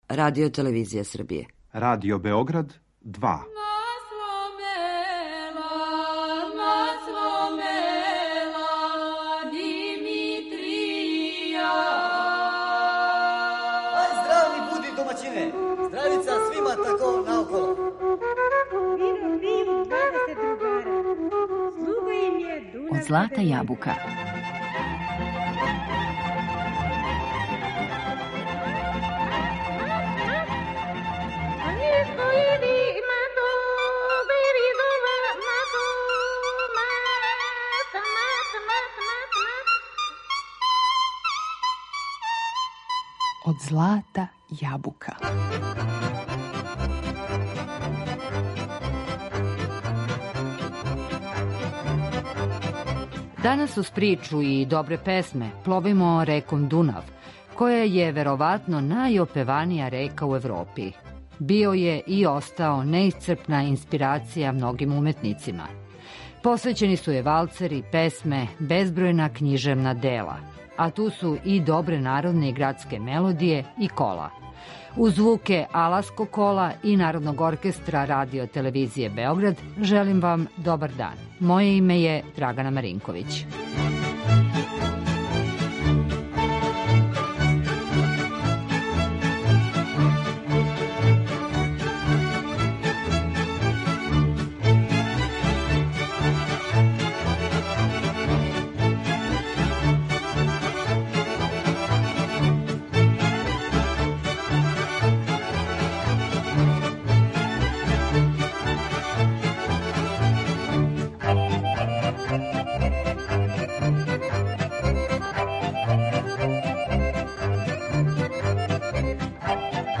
Данас кроз причу и добре песме пловимо реком Дунав.